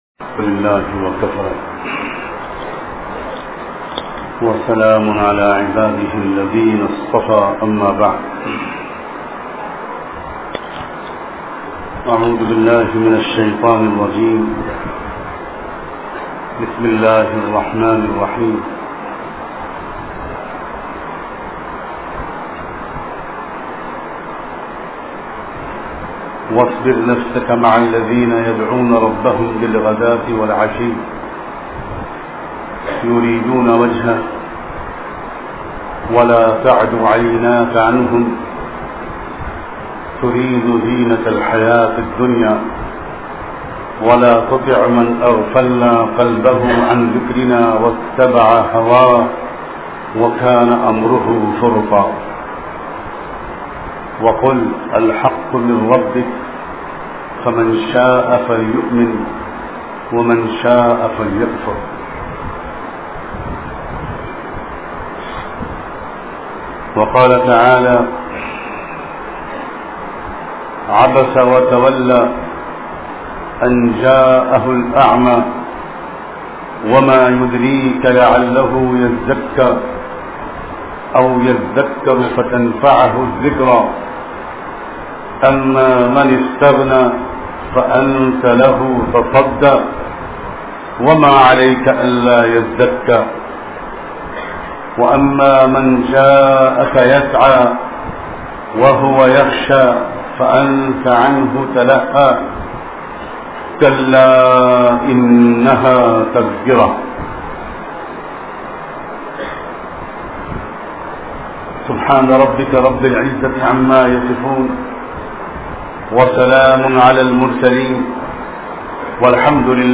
Bada Banne Sey Pehle Chota Banna Zaroori Hai bayan MP3